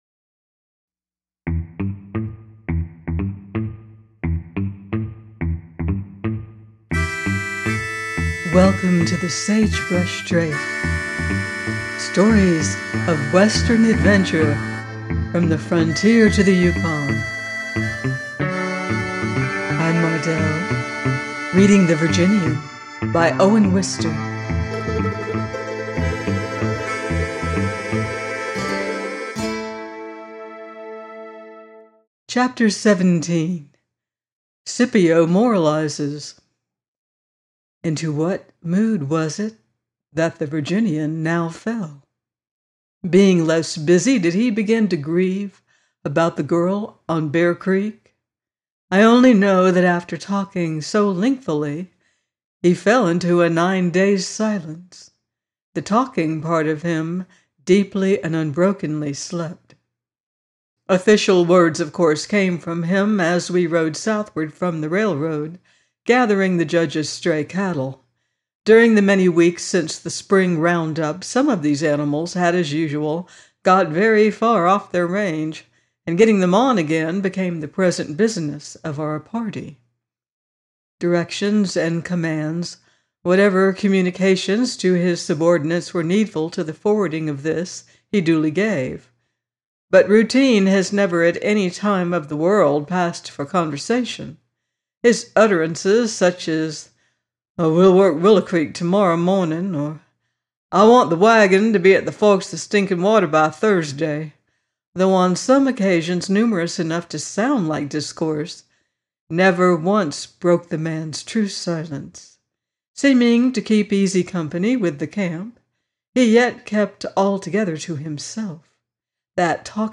The Virginian 17 - by Owen Wister - audiobook